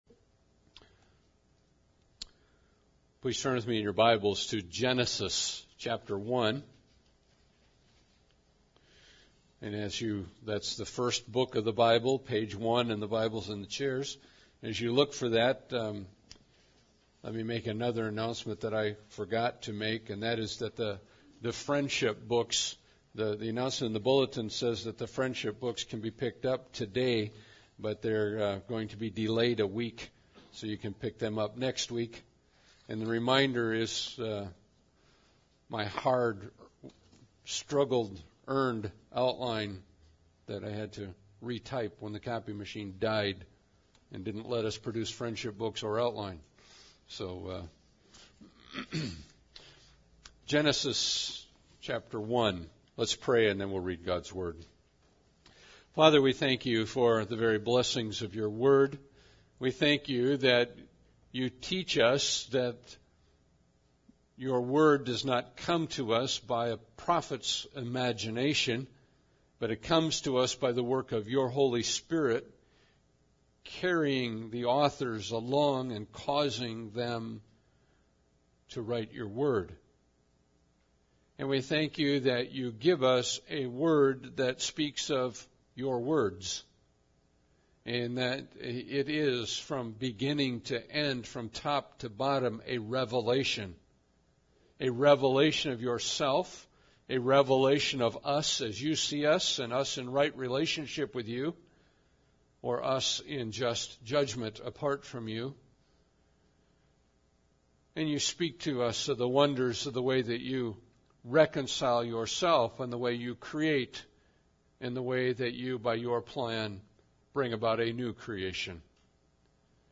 Passage: Genesis 1 Service Type: Sunday Service